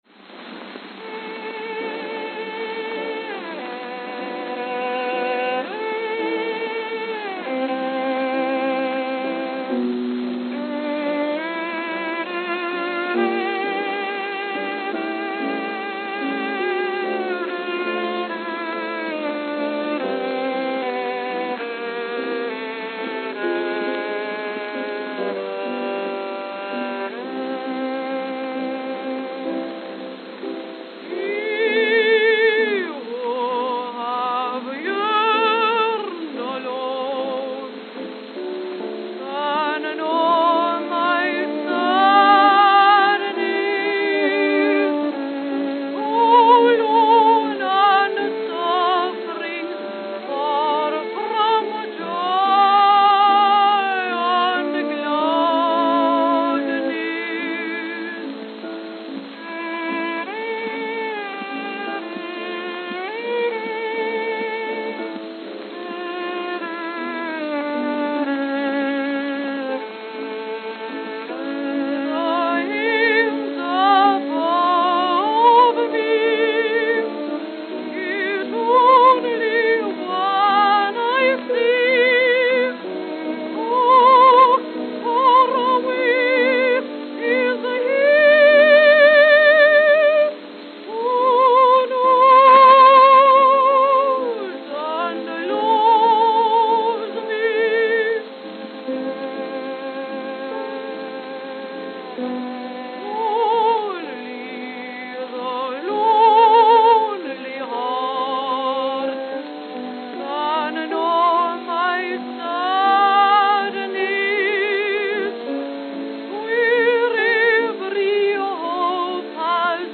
Note: Warped.